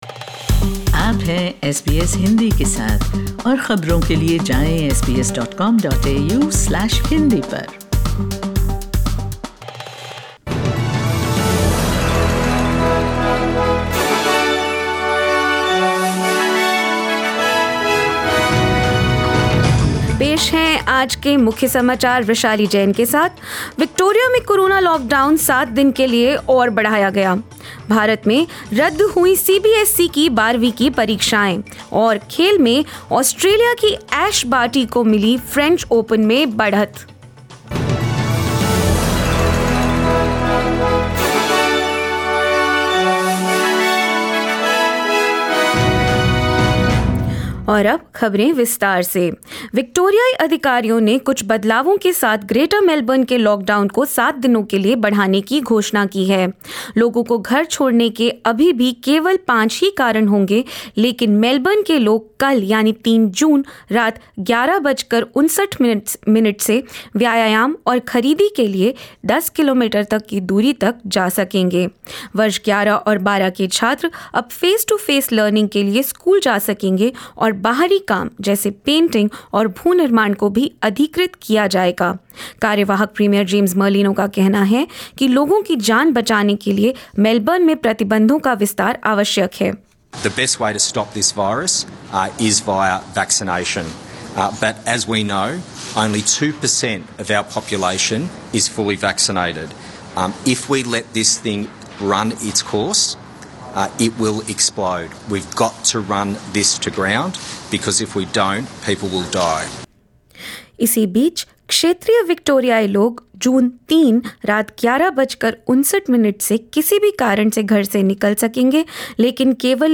In this latest SBS Hindi News bulletin covering Australia and India: Victorian coronavirus lockdown extended for seven days as new cases were recorded in Melbourne; In India, CBSE class 12th Board exams stand cancelled and more. 02/06/21